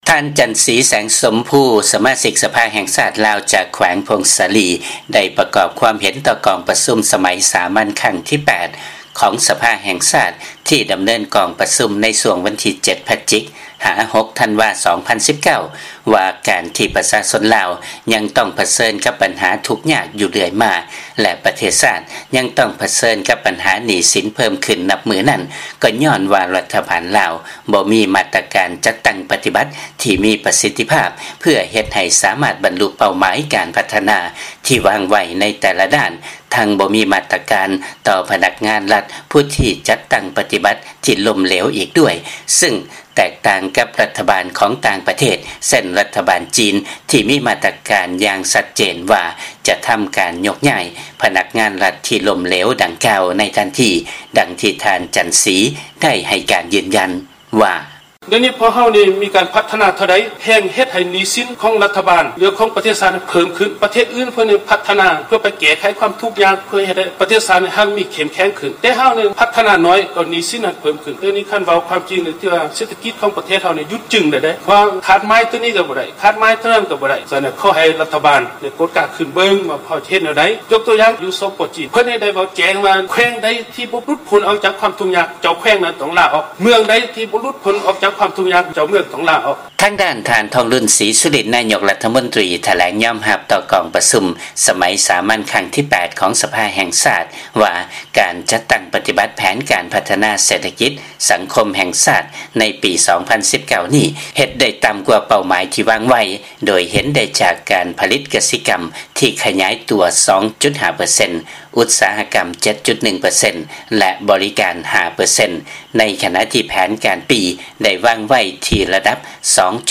ຟັງລາຍງານ ລັດຖະບານ ລາວ ບໍ່ມີມາດຕະການ ເພື່ອເຮັດໃຫ້ສາມາດ ບັນລຸເປົ້າໝາຍ ການພັດທະນາທີ່ວາງໄວ້